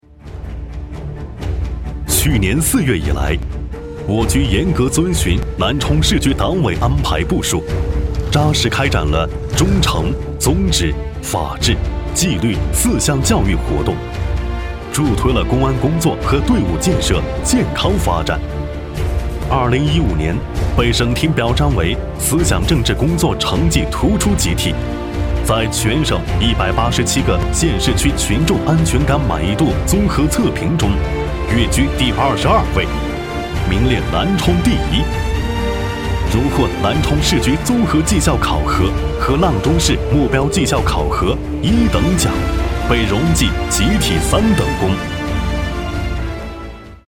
政府男21号（公安局）
年轻时尚男中音，大气浑厚，洪亮。擅长旁白讲述，专题，宣传片，mg动画题材。